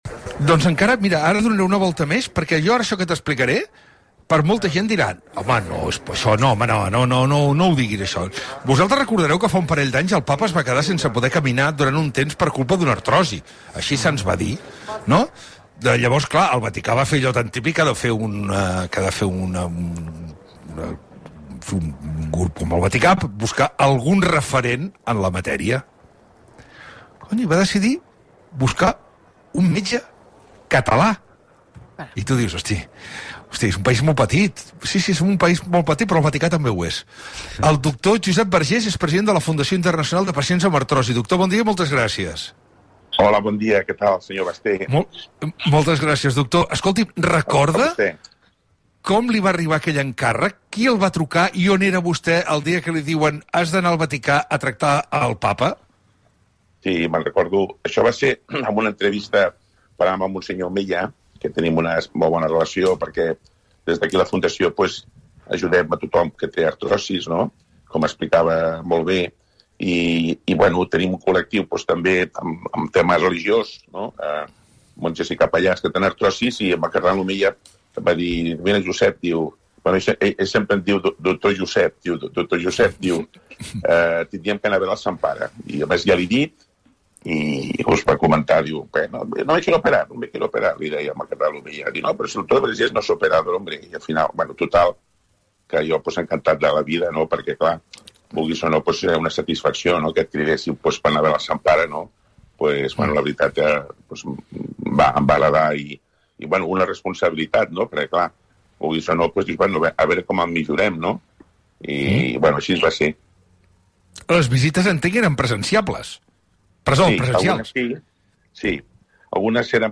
A continuación, os dejamos con la reproducción de la entrevista realizada por el periodista Jordi Basté, en el programa El Món a RAC1.